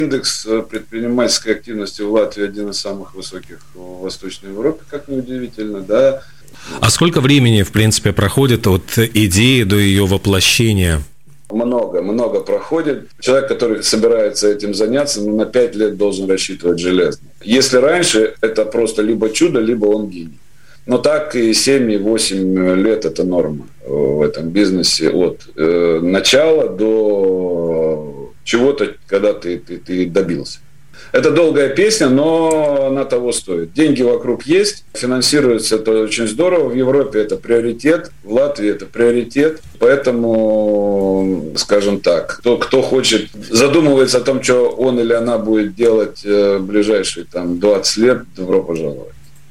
На радио Baltkom сегодня обсуждали такие темы как онлайн-концерты и занятия спортом в период пандемии, а также возможность получить финансовую поддержку на реализацию идей.